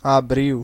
Ääntäminen
IPA: /apˈriːl/